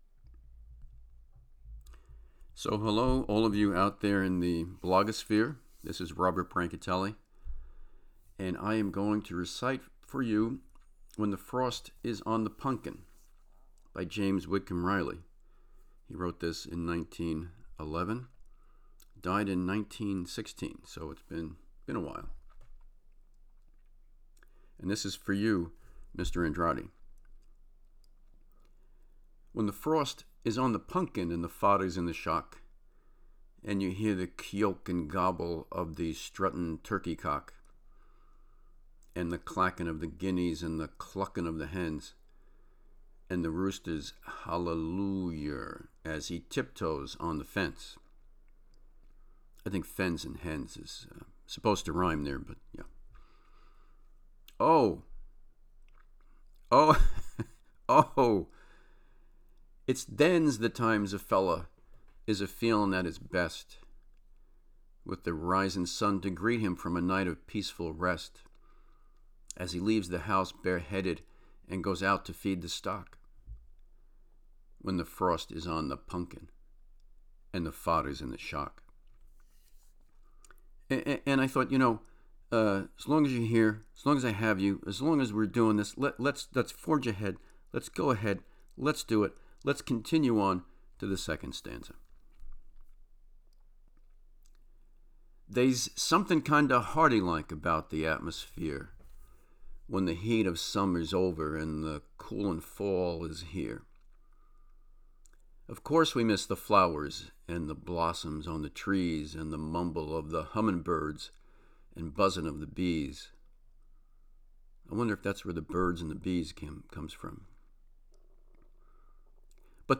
I’ve been to Indiana, of course, and even gotten lost there (see Bloomington Blues #3), but my phrasing and cadence are all Staten Island.